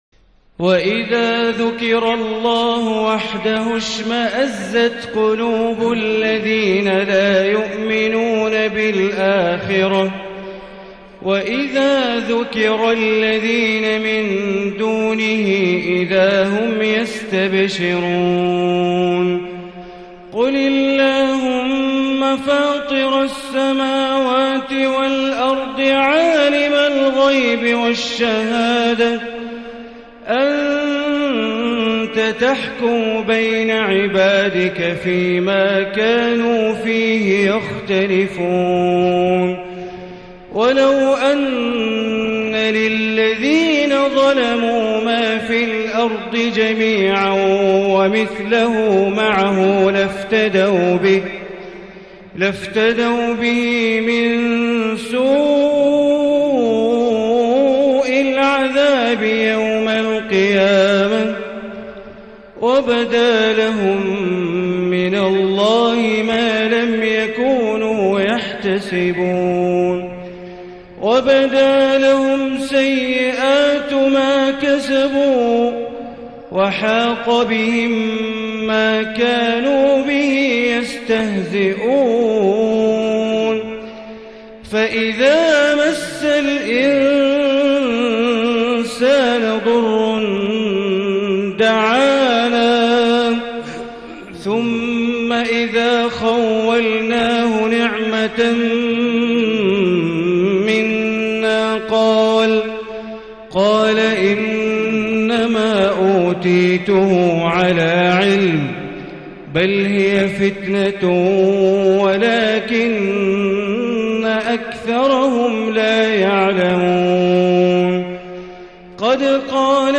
عشاء الجمعه 1 رجب 1440هـ من سورة الزمر | Isha prayer 8-3-2019 from Surah Az-Zumar > 1440 🕋 > الفروض - تلاوات الحرمين